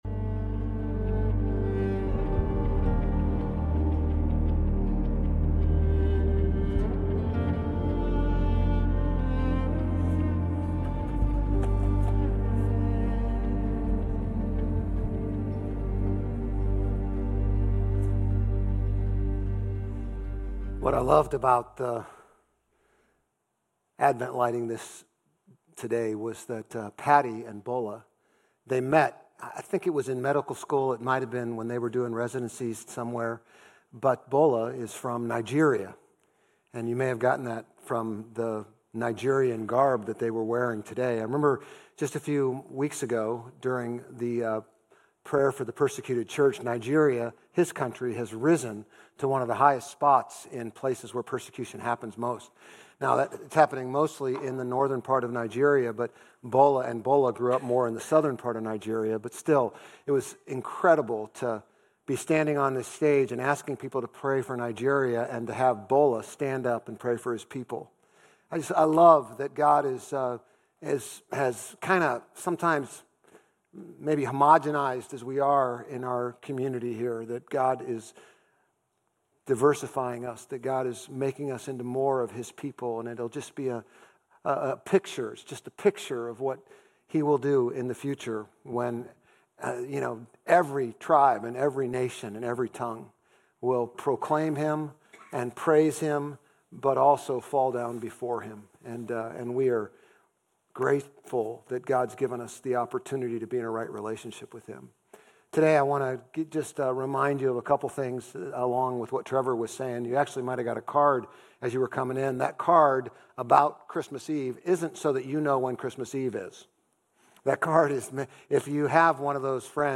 Grace Community Church Old Jacksonville Campus Sermons 12_14 Old Jacksonville Campus Dec 15 2025 | 00:35:16 1x 00:00 / 00:35:16 Subscribe Share RSS Feed Share Link Embed